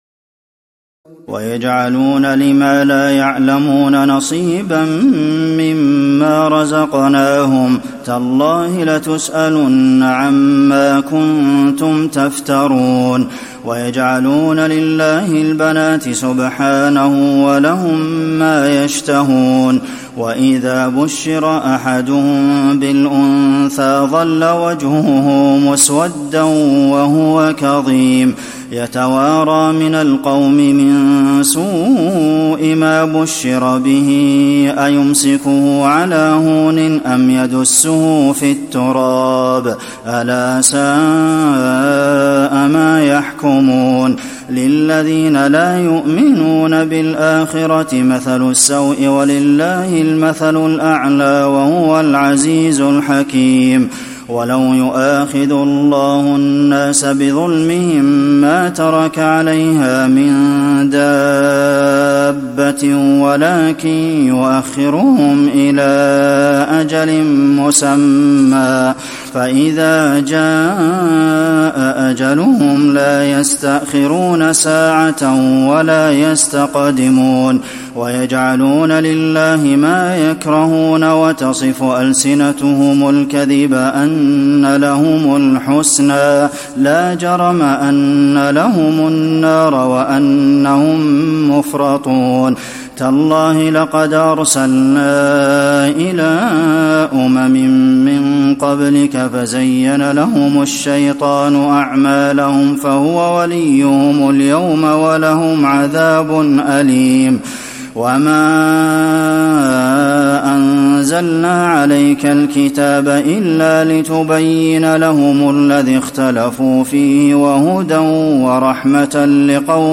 تراويح الليلة الثالثة عشر رمضان 1435هـ من سورة النحل (56-128) Taraweeh 13 st night Ramadan 1435H from Surah An-Nahl > تراويح الحرم النبوي عام 1435 🕌 > التراويح - تلاوات الحرمين